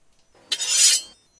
sword.9.ogg